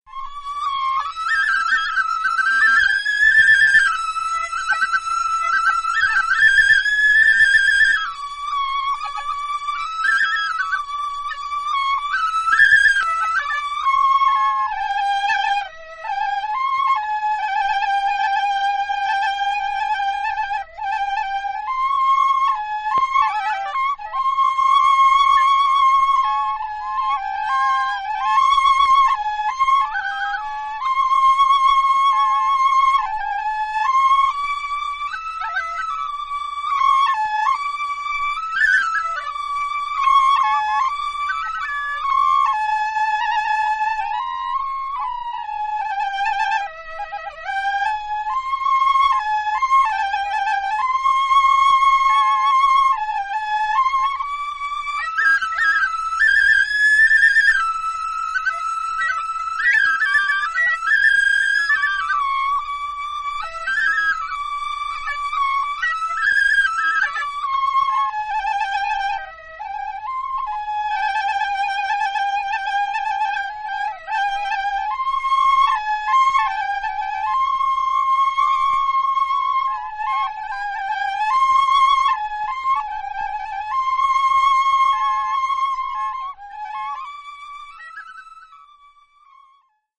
バリの伝統的古典音楽をコンパイルしたオムニバス。
Suling Duo "Sekar Lelet"